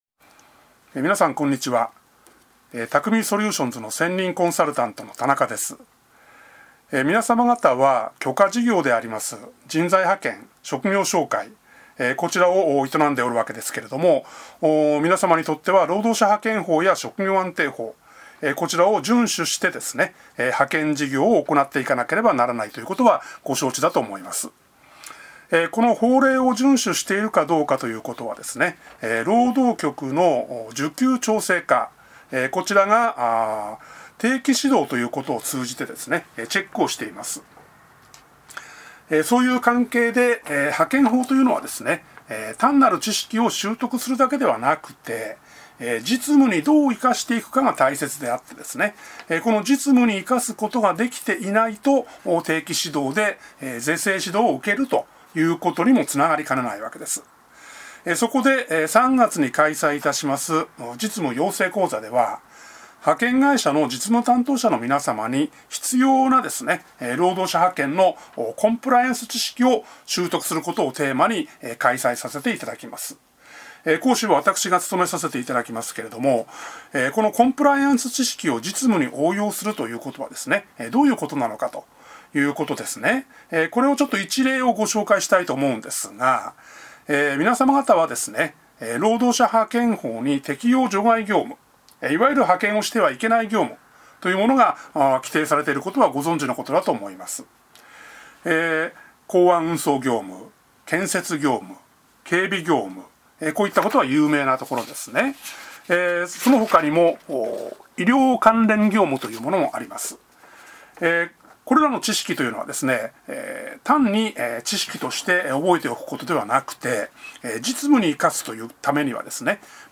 音声解説